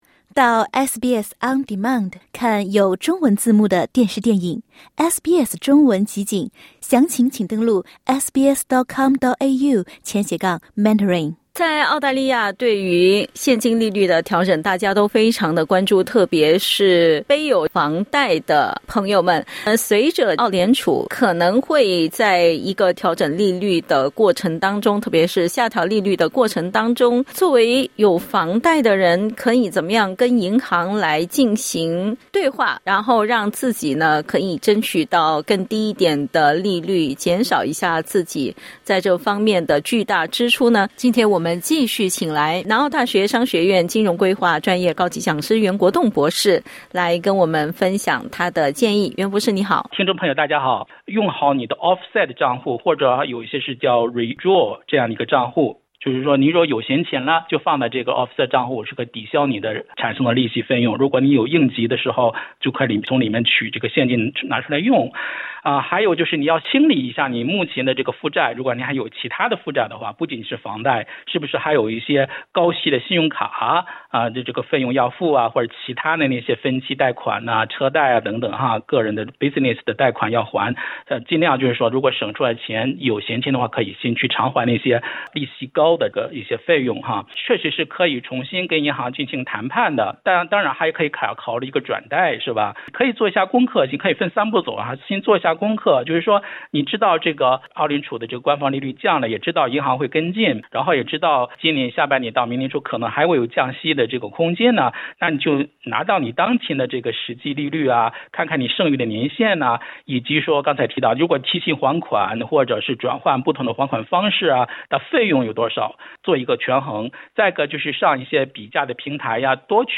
（点击音频收听详细采访） 根据房地产数据分析公司Cotality的数据，澳洲全国平均楼价在八月上升0.7%，其中阿德雷德的升幅高达1.2%。